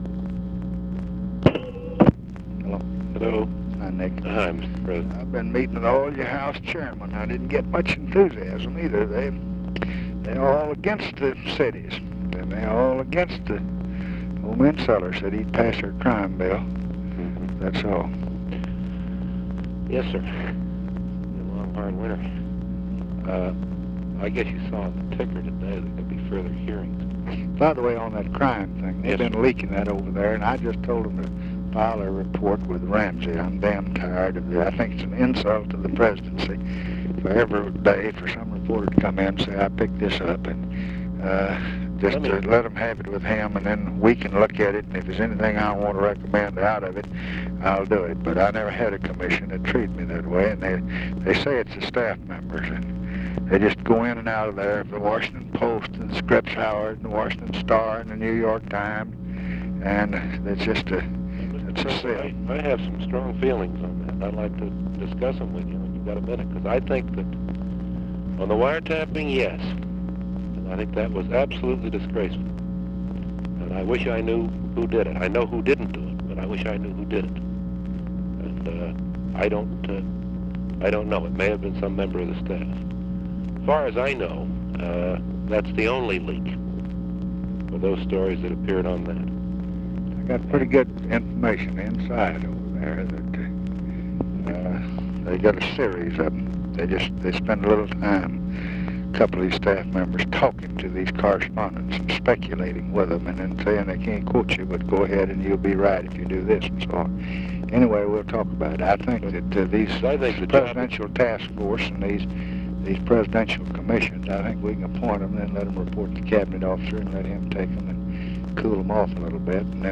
Conversation with NICHOLAS KATZENBACH, January 26, 1967
Secret White House Tapes